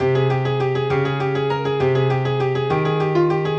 Notez le changement de mesure.